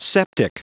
Prononciation du mot septic en anglais (fichier audio)
Prononciation du mot : septic